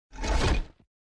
ui_interface_32.wav